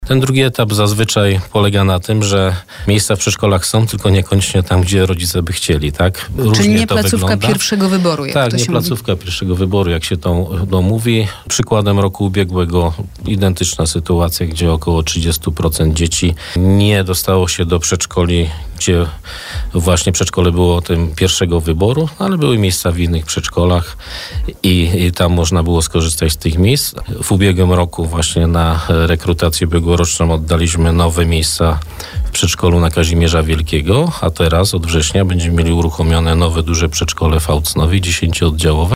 Skontaktować się z nauczycielkami, czy z dyrektorem przedszkola i wszelkie takie informacje Państwo uzyskacie, czy też [pytać – red.] u nas w urzędzie miasta – mówił na naszej antenie Piotr Kucia, wiceprezydent Bielska-Białej.